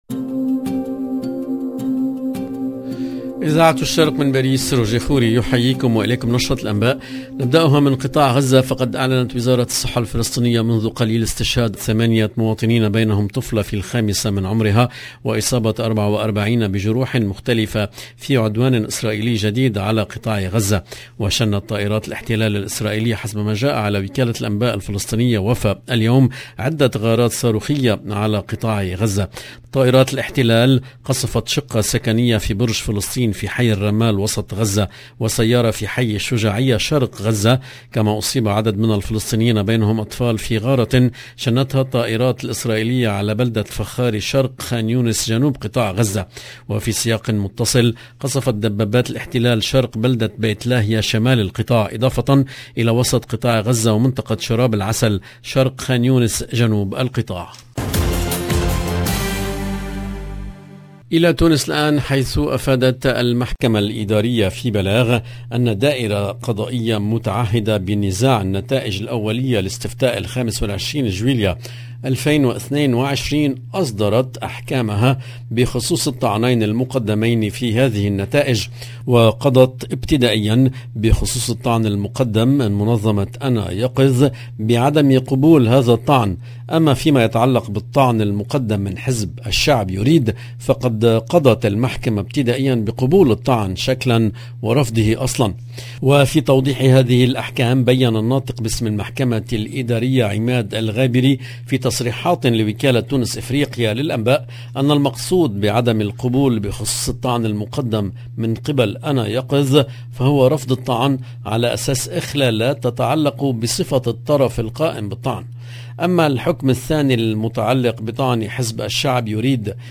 LE JOURNAL DU SOIR EN LANGUE ARABE DU 5/08/22